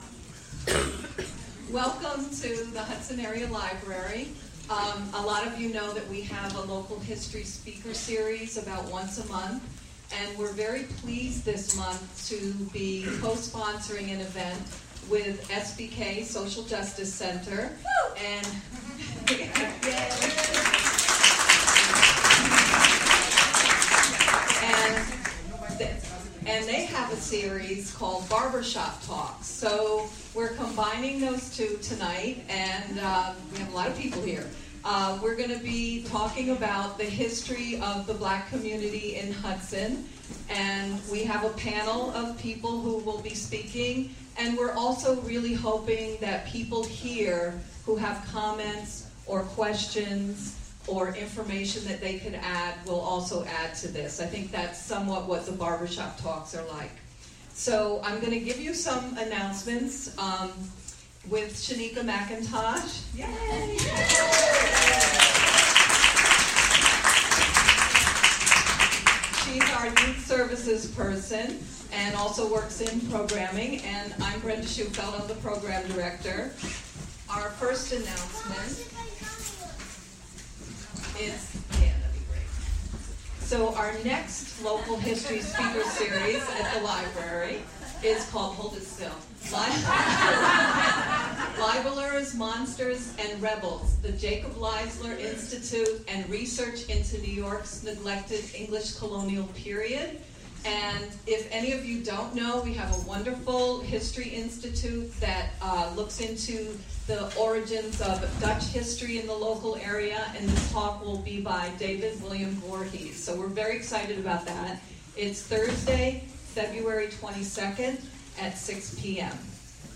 Recorded from a program at the Hudson Area Library Feb. 8. Longtime Hudson residents will speak about their roots in and/or migration to Hudson. They will discuss memories of the Hudson they grew up in and what Hudson is like now.
A question and answer period and refreshments will follow the discussion.